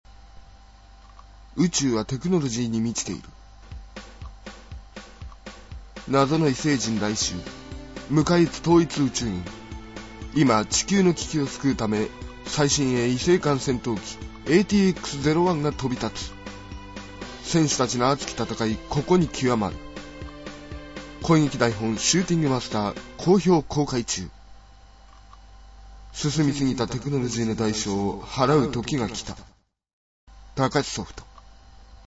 声劇台本「シューティングマスター」ＣＭモドキ